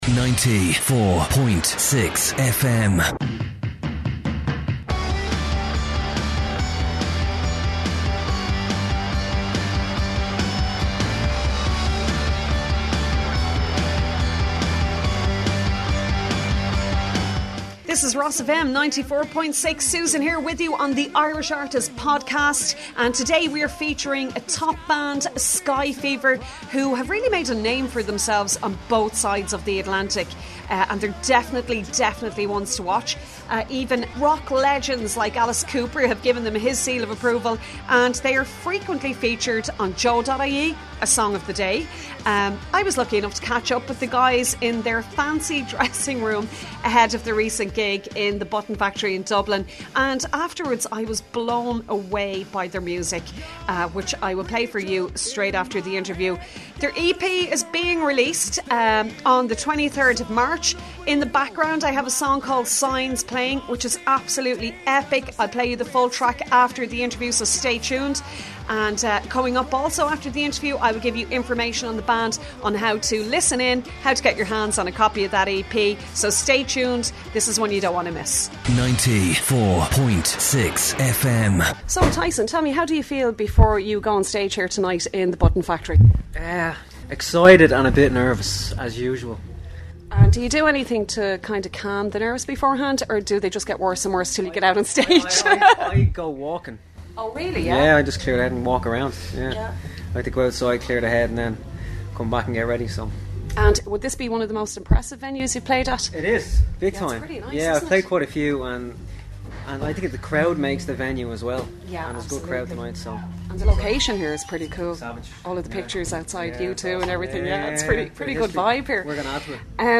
Irish Rock Band Skyfever Interview - RosFM 94.6